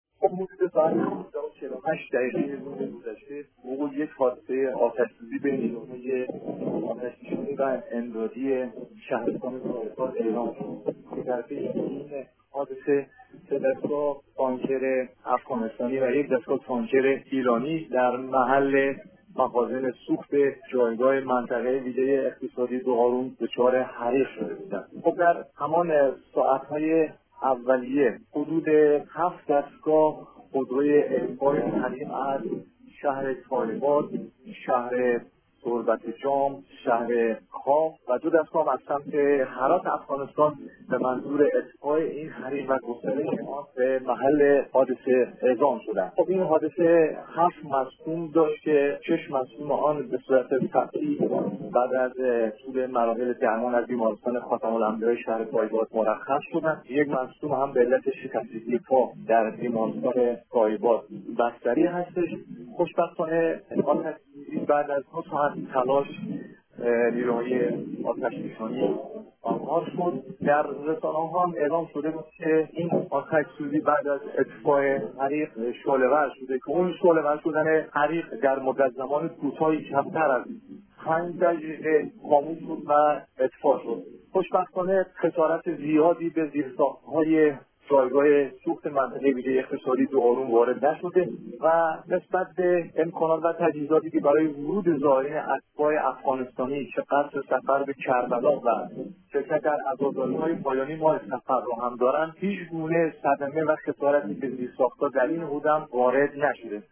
گزارش تکمیلی از مهار آتش سوزی در گذرگاه مرزی دوغارون